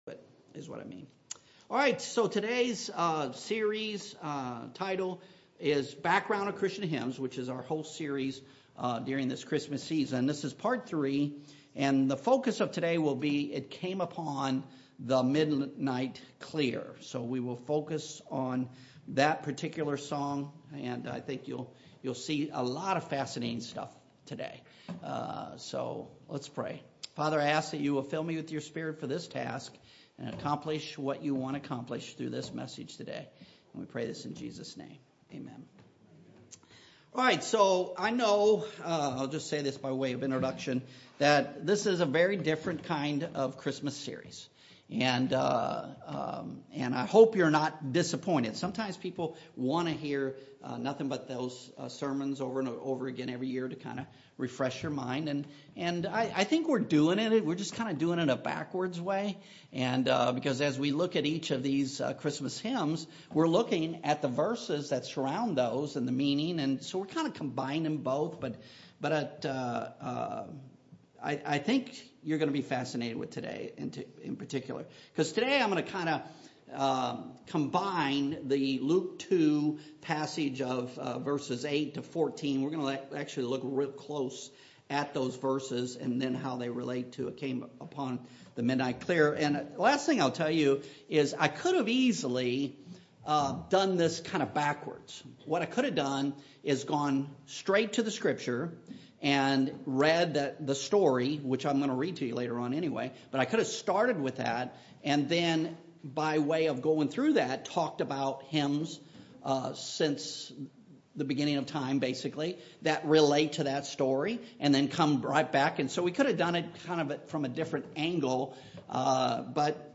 Sunday Morning Worship Service – December 15, 2024 First Church of the Nazarene, Republic, MO Speaker